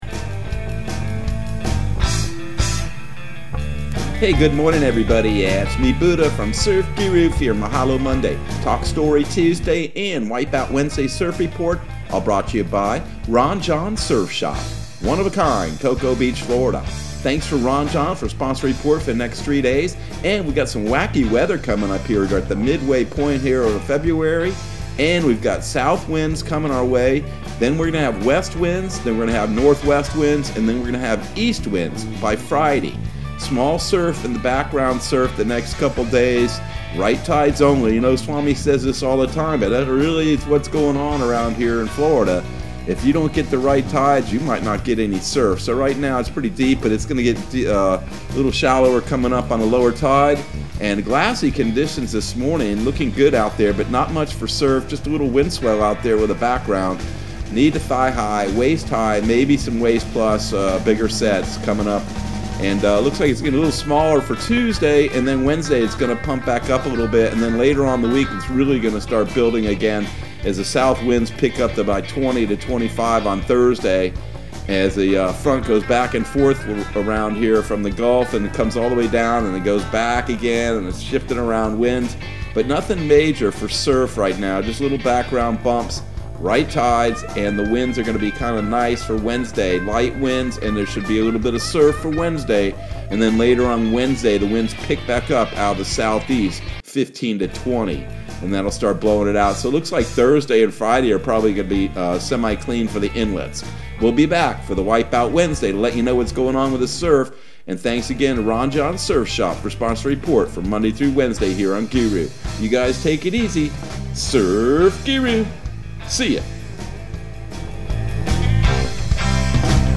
Surf Guru Surf Report and Forecast 02/15/2021 Audio surf report and surf forecast on February 15 for Central Florida and the Southeast.